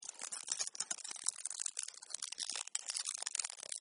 Шорох прыжка блохи